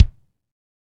Index of /90_sSampleCDs/Northstar - Drumscapes Roland/KIK_Kicks/KIK_A_C Kicks x
KIK A C K0BR.wav